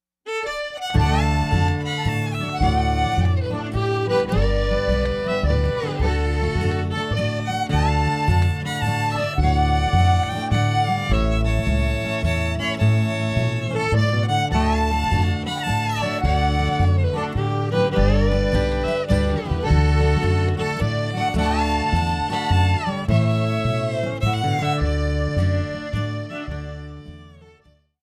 Old Time Music of SW Pennsylvania
fiddle
banjo, fife, accordion
guitar
upright bass Between 1928 and 1963